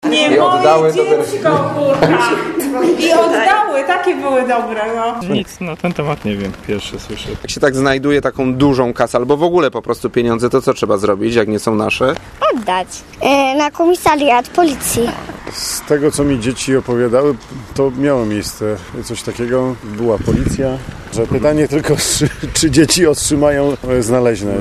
tkbq4ahqa830ba5_rozmawial-z-mieszkancami-osiedla-o-znalezisku.mp3